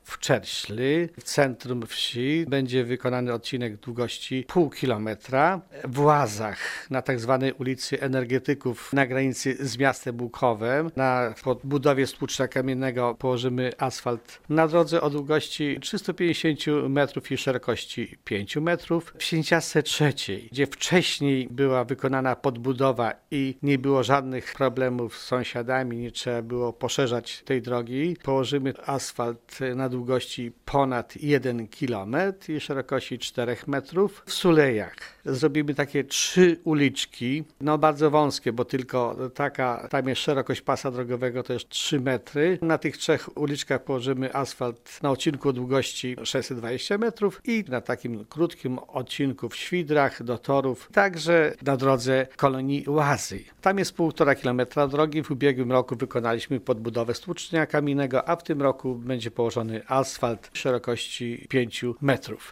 O szczegółach, planowanych inwestycji informuje zastępca wójta Wiktor Osik: